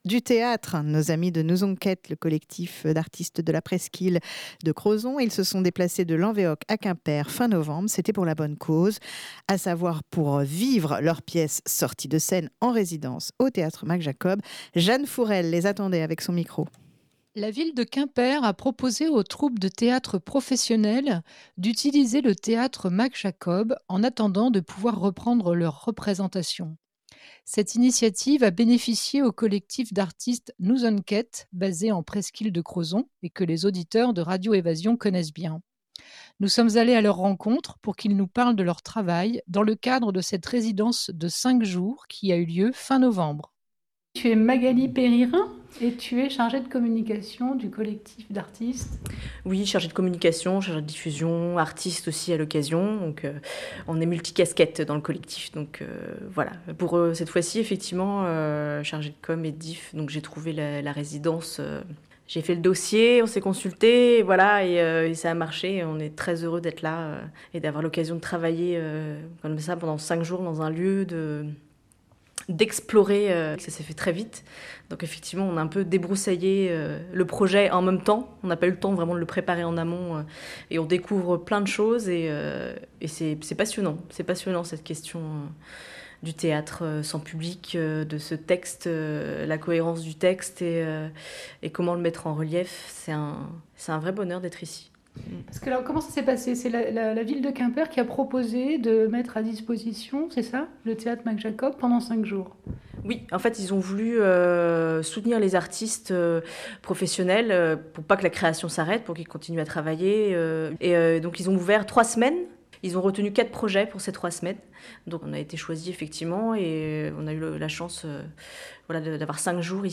Nous sommes allés à la rencontre des membres de la compagnie qui nous ont présenté leur travail dans le cadre de cette résidence de 5 jours qui a eu lieu fin novembre 2020.